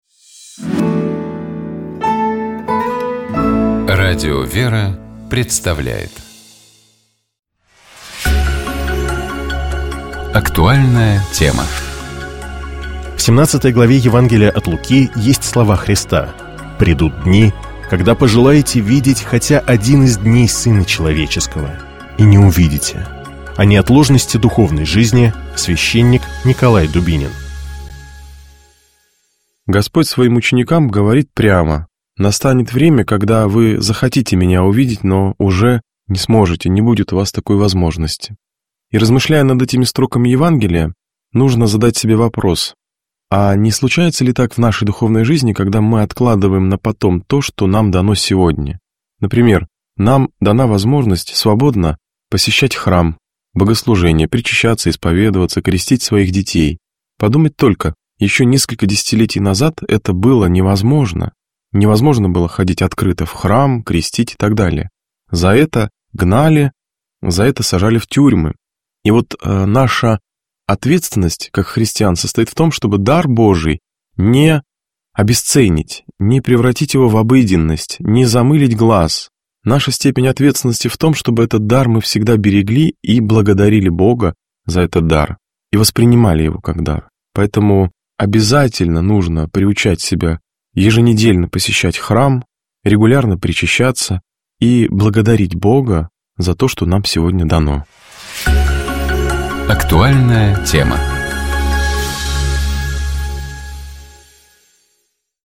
В студии светлого радио приветствую вас я